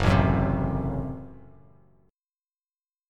Gbdim chord